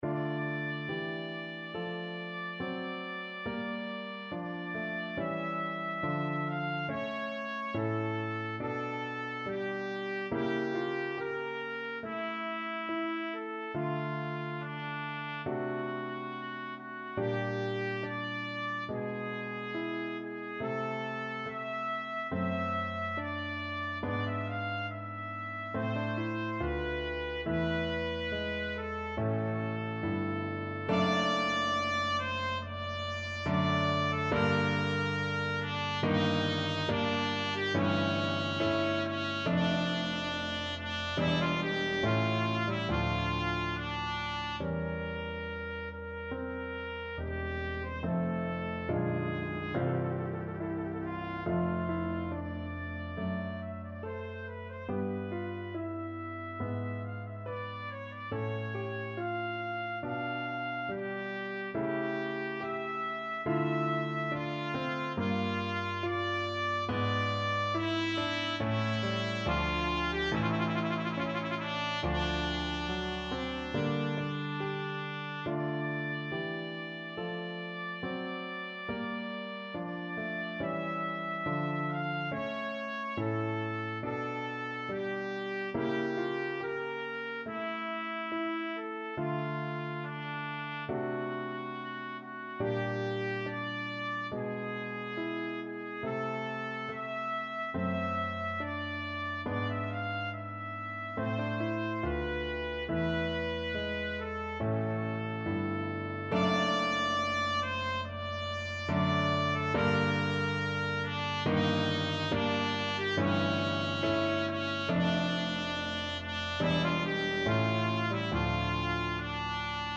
Trumpet
D minor (Sounding Pitch) E minor (Trumpet in Bb) (View more D minor Music for Trumpet )
4/4 (View more 4/4 Music)
Adagio =70
Classical (View more Classical Trumpet Music)
trio-sonata-in-g-minor-z-780_TPT.mp3